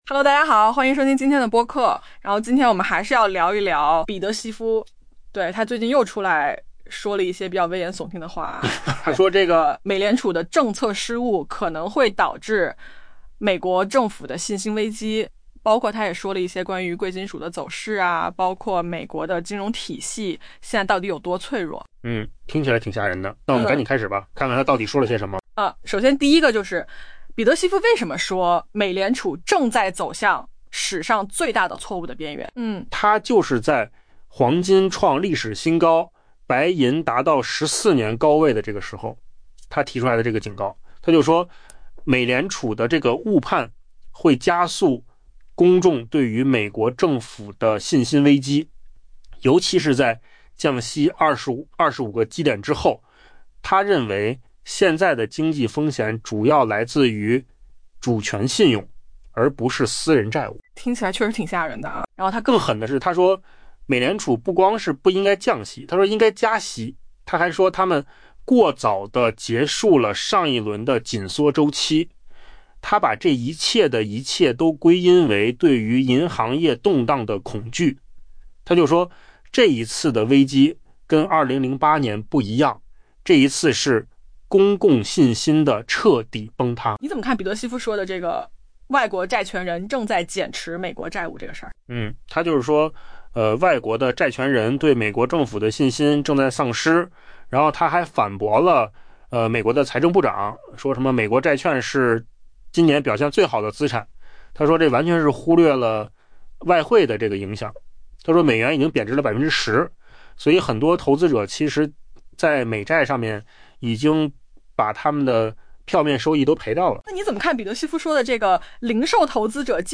AI 播客：换个方式听新闻 下载 mp3 音频由扣子空间生成 周二在黄金创下历史新高、白银触及 14 年高位之际，经济学家彼得·希夫 （Peter Schiff） 警告称， 美联储正濒临犯下 「史上最大错误」 的边缘 ，他认为这一举动将加速引发对美国政府本身的信心危机。